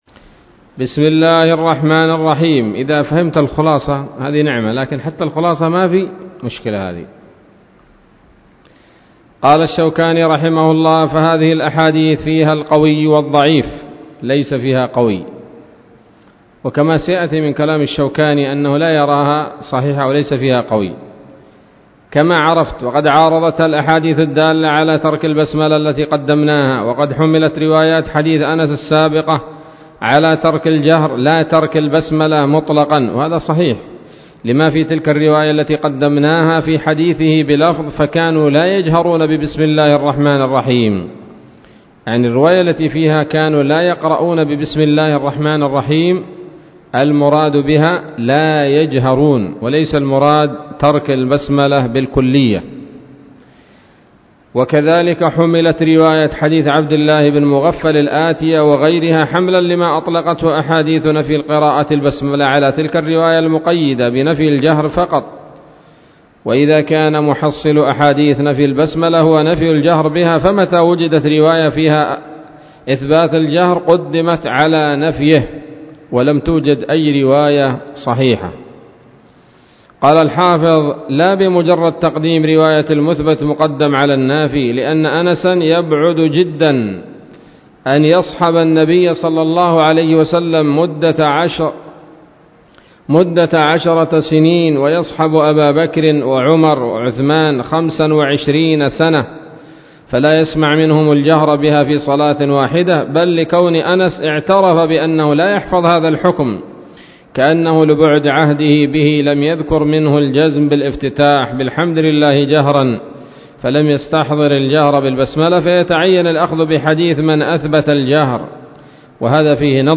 الدرس الثالث والعشرون من أبواب صفة الصلاة من نيل الأوطار